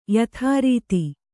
♪ yathārīti